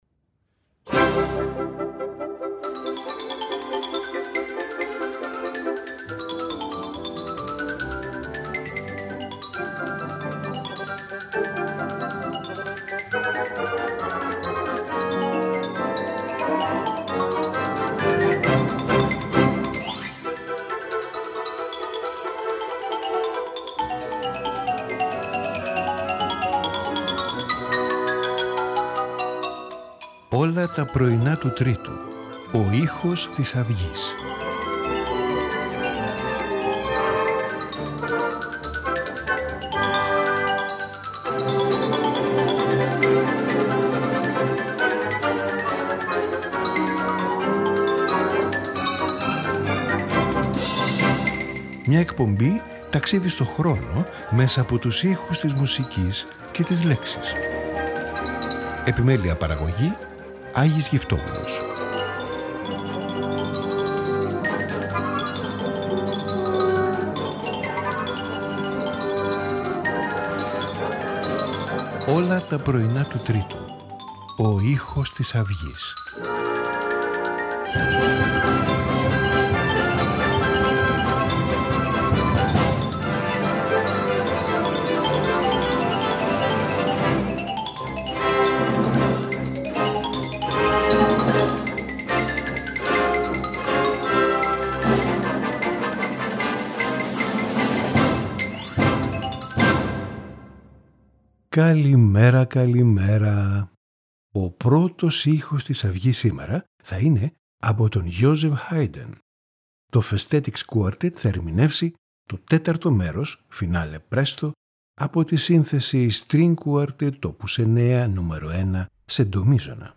Joseph Haydn – String Quartet Op. 9, No 1, in C, Part IVW. A. Mozart – Piano Trio in E, K. 542, Part IDomenico Scarlatti – Sonata in G minor K 4J.
Piano Concerto
Sonata for Flute and Piano